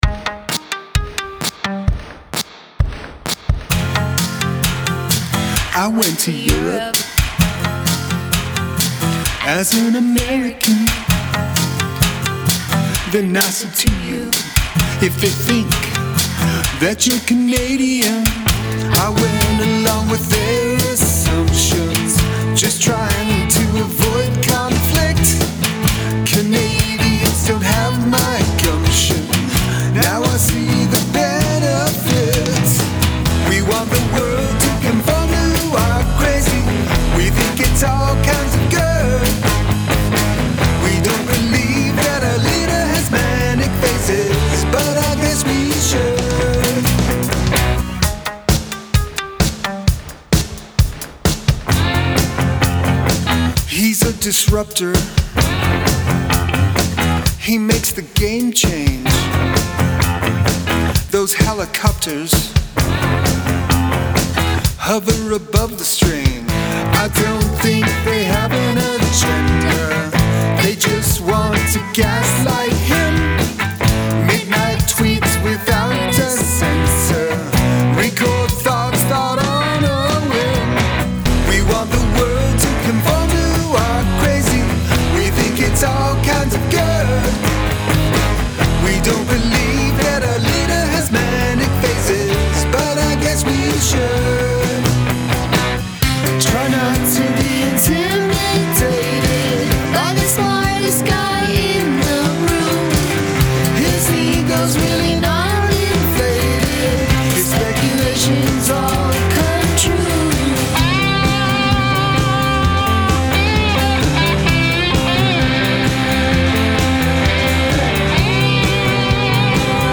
Have a guest play a household item on the track